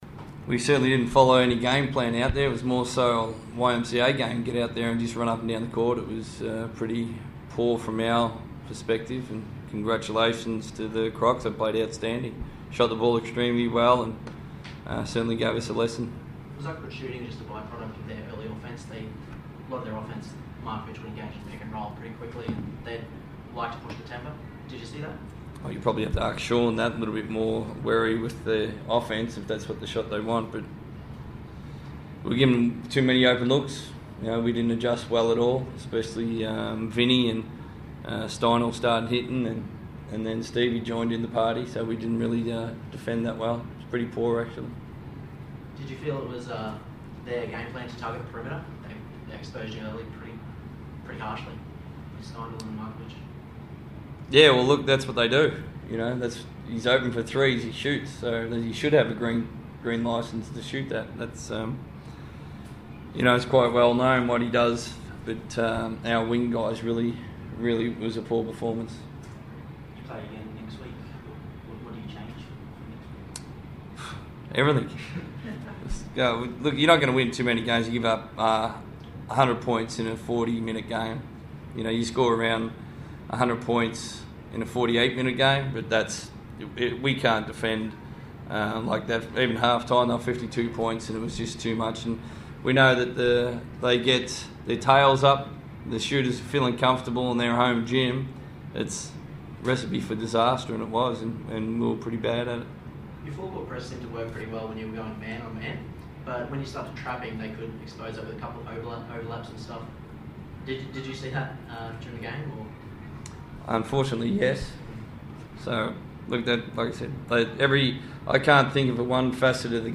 speak to the media after going down to the Townsville Crocodiles.